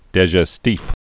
(dĕzhĕs-tēf)